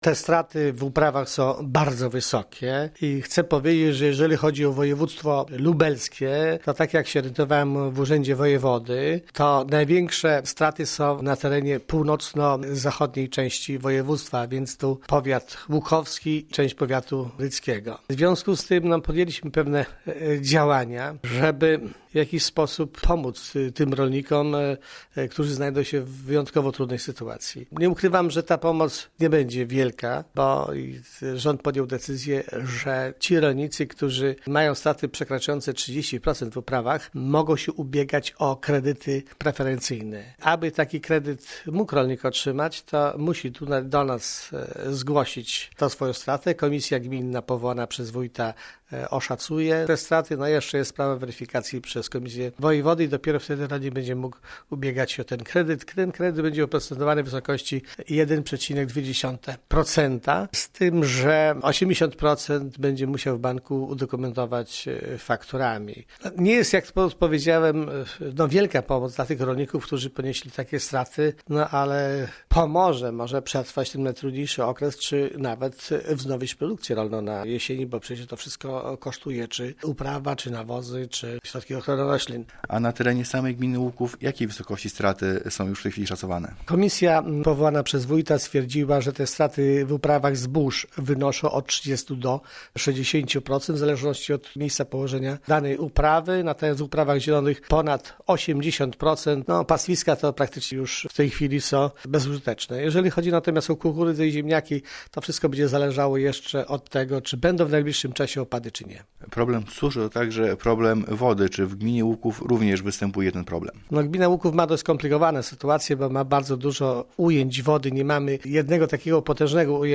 Z- ca Wójta Gminy Łuków